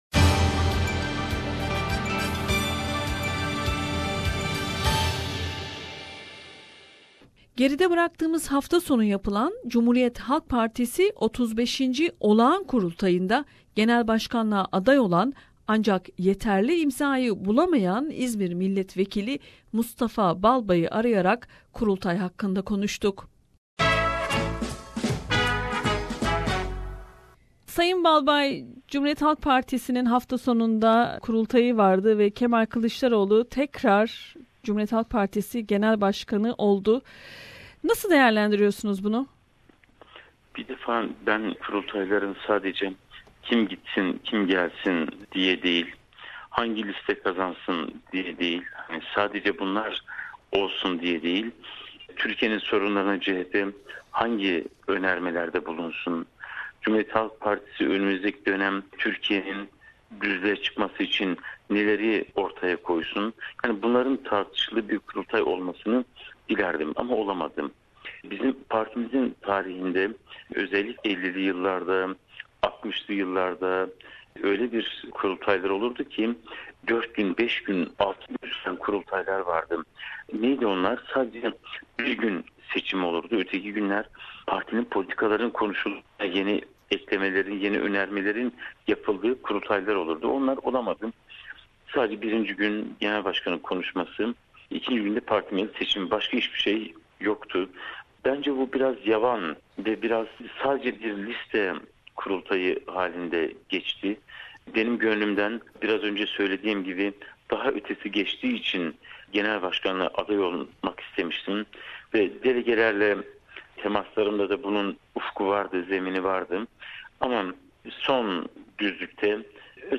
Geride bıraktığımız haftasonu yapılan CHP 35. Olağan Kurultayında, Genel Başkanlığa aday olan ancak yeterli imzayı alamayan İzmir milletvekili Mustafa Balbay'ı arayarak kurultay hakkında konuştuk.